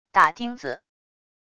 打钉子wav音频